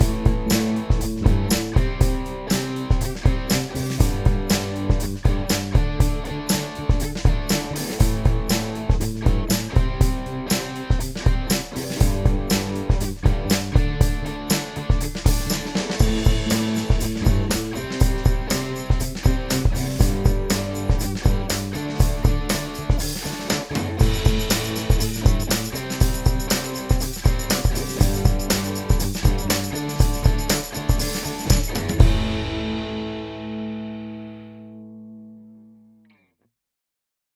The pickups the sound good and as a bonus the back pick splits amazingly.